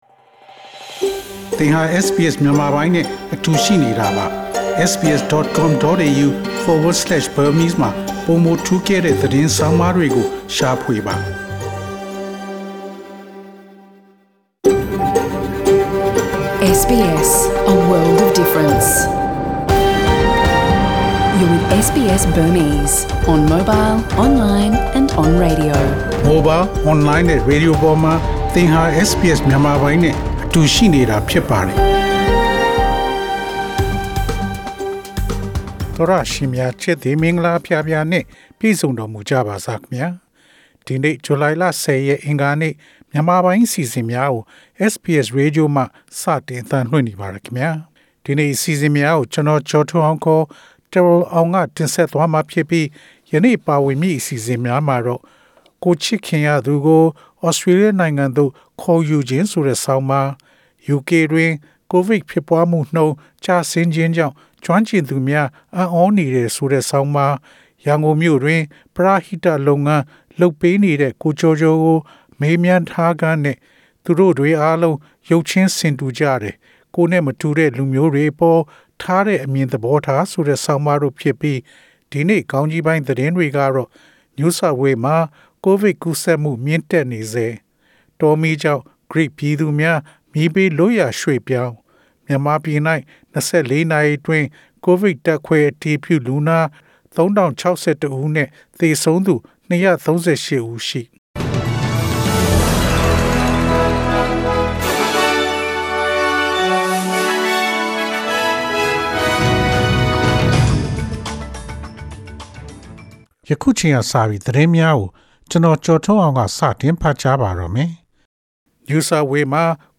SBS မြန်မာပိုင်း အစီအစဉ် ပေါ့ကတ်စ် သတင်းများ။